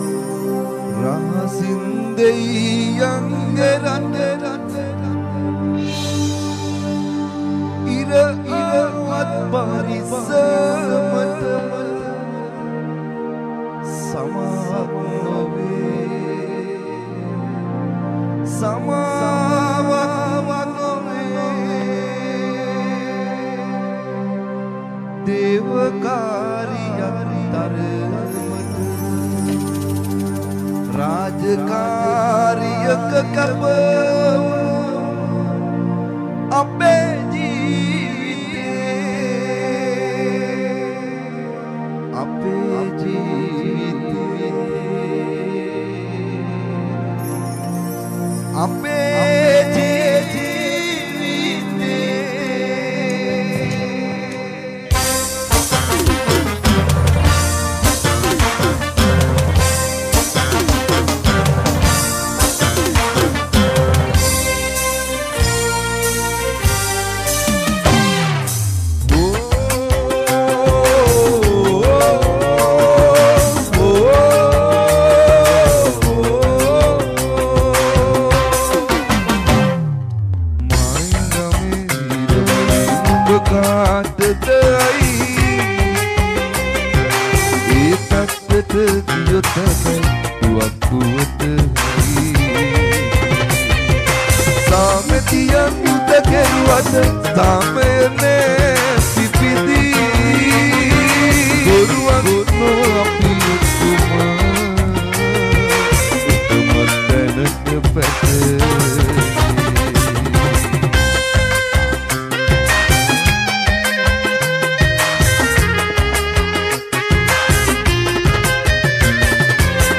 Live in Harindragama